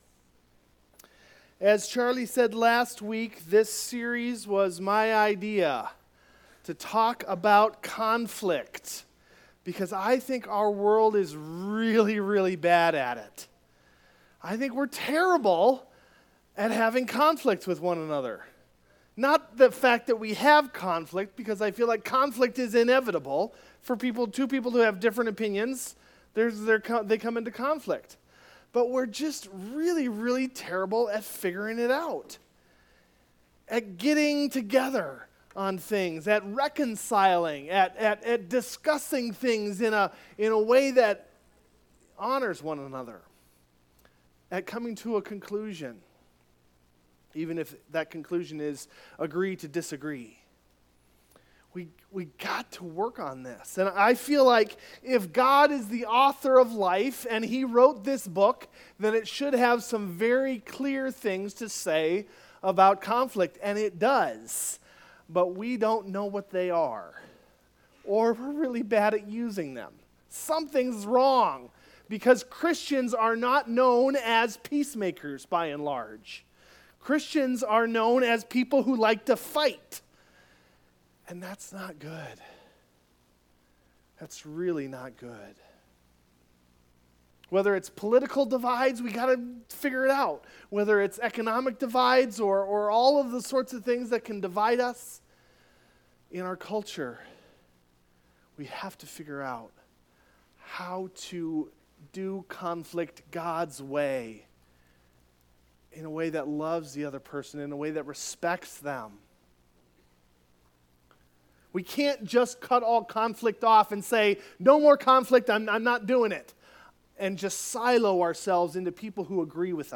Video Audio Download Audio Home Resources Sermons Fight Fair Oct 19 Fight Fair Conflict is inevitable, so we’ve got to figure out the rules. Let’s talk through five principles from God’s word on how to Fight Fair.